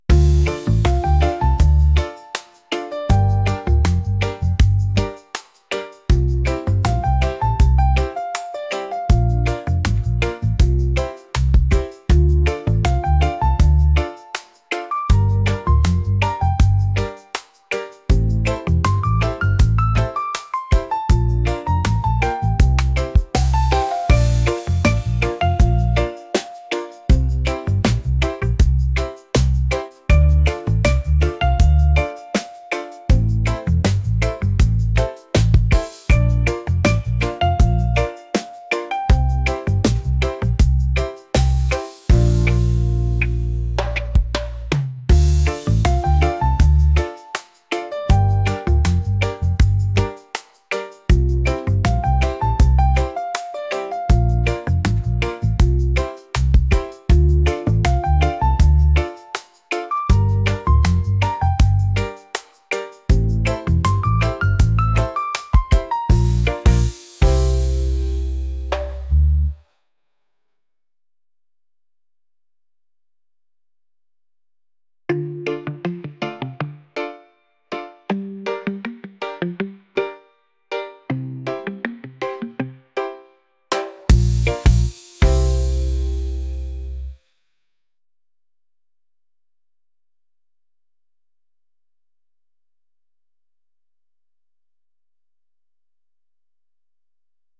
reggae | laid-back